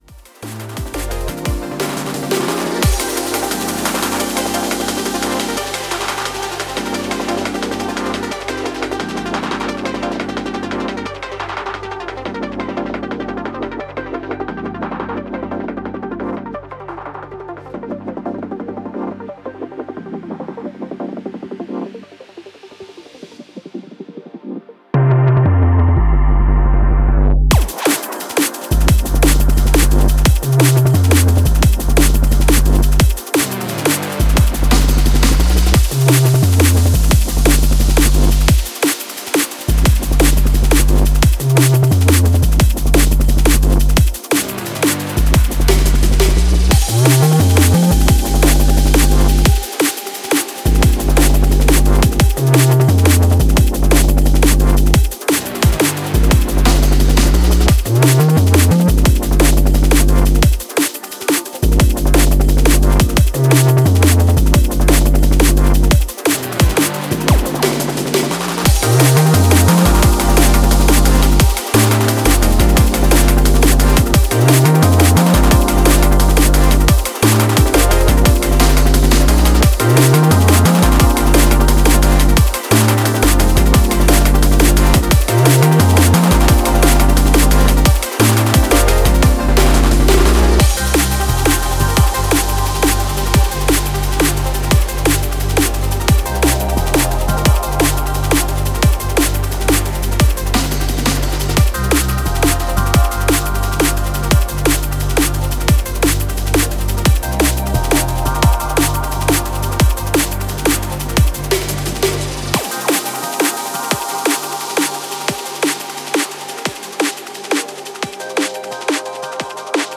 drum and bass
electronic music